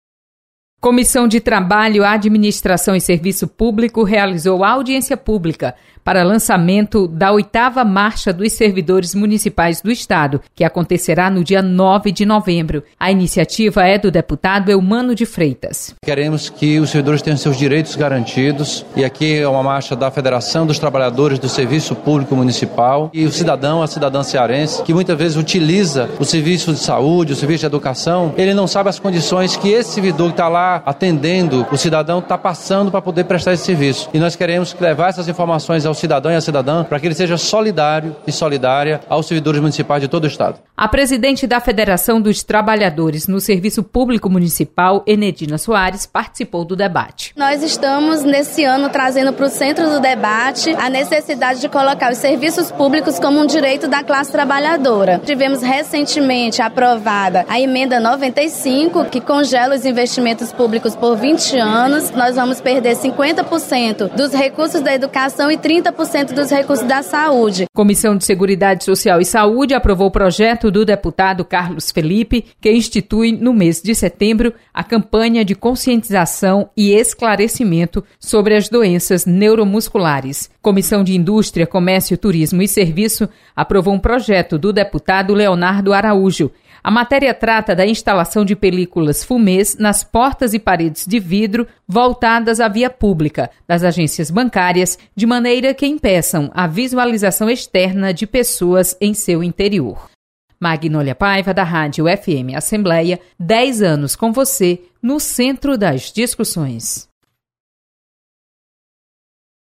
FM Assembleia